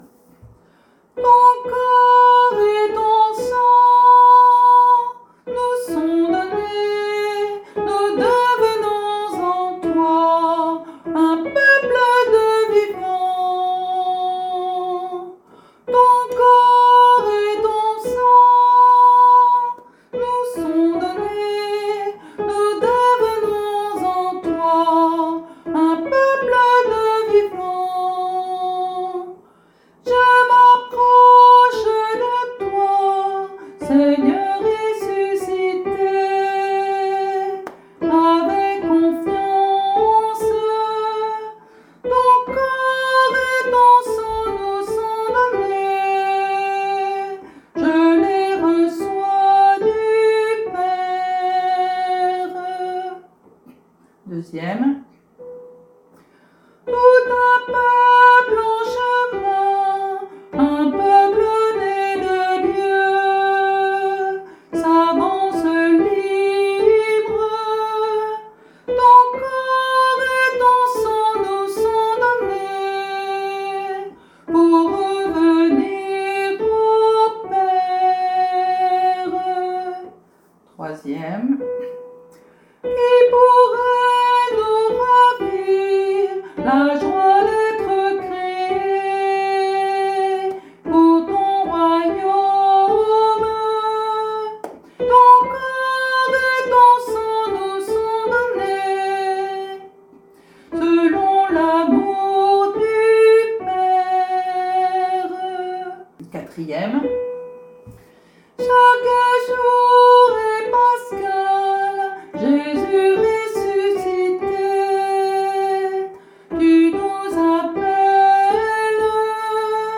Mélodie